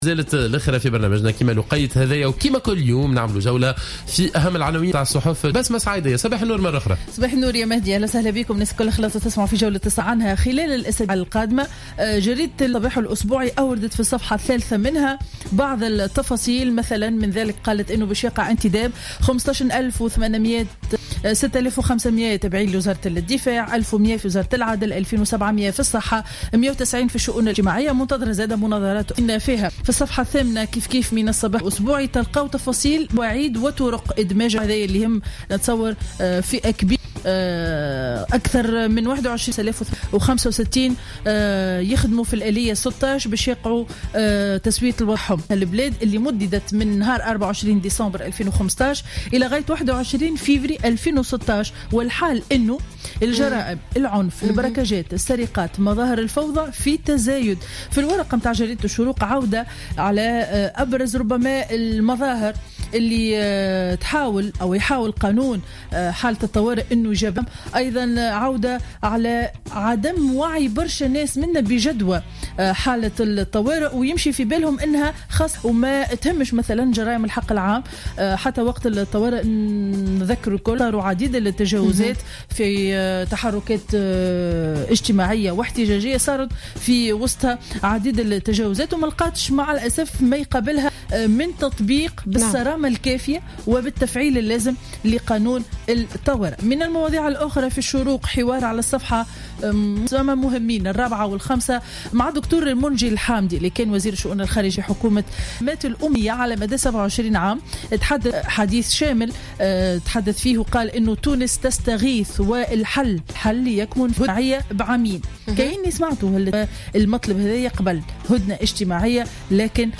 Revue de presse du lundi 8 février 2016